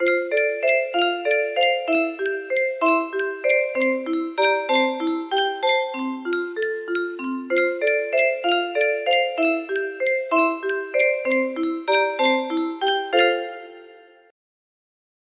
Yunsheng Music Box - Jesus Wants Me For a Sunbeam Y688 music box melody
Full range 60